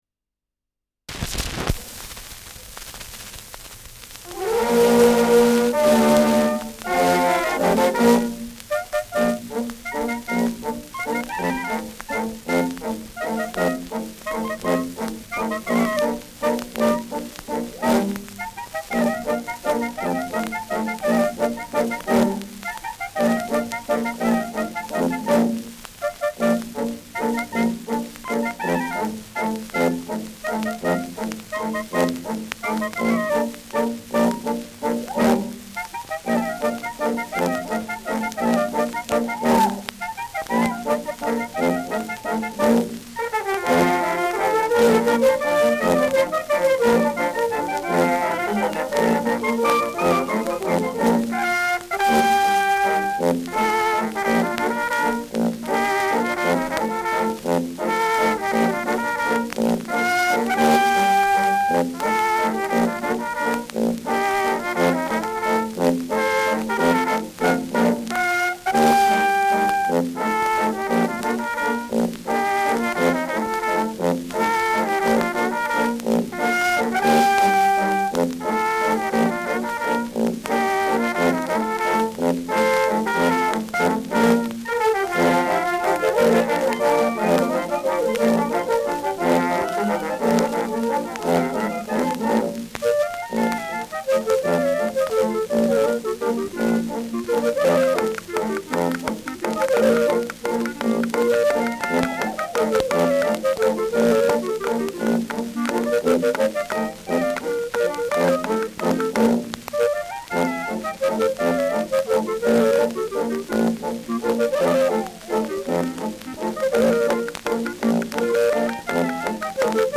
Schellackplatte
Stärkeres Grundrauschen : Gelegentlich leichtes bis stärkeres Knacken : Verzerrt an lauten Stellen
[München] (Aufnahmeort)